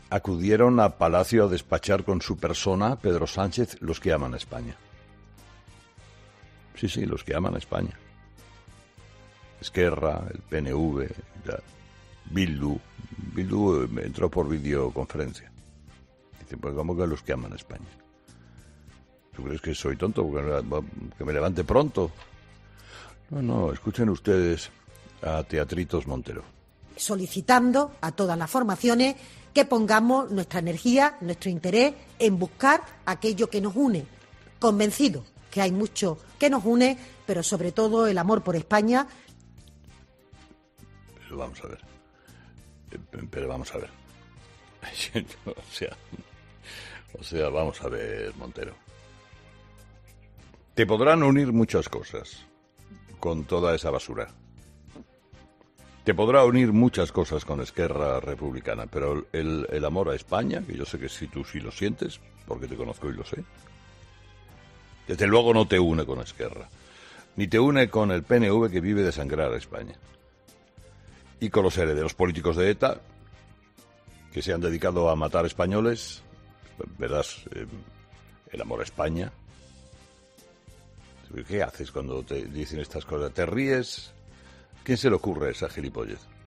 El director de 'Herrera en COPE', Carlos Herrera, quiso matizar las palabras de la ministra María Jesús Montero